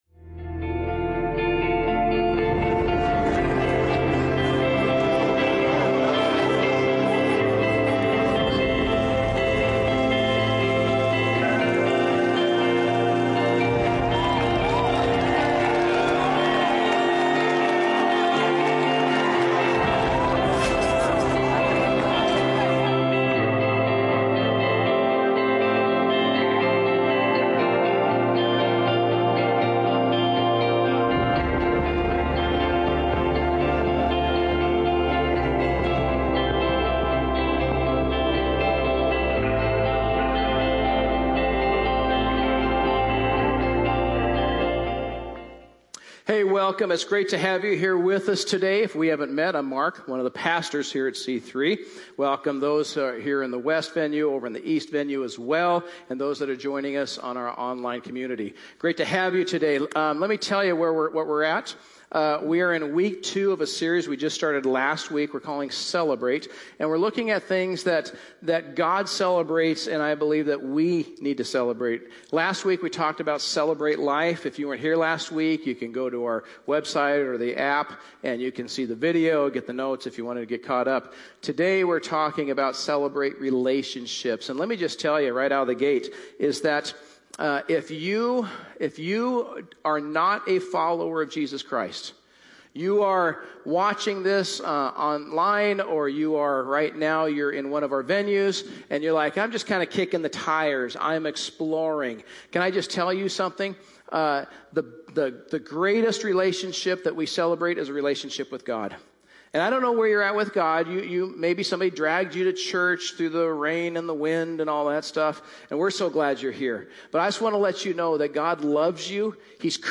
A message from the series "Unstoppable."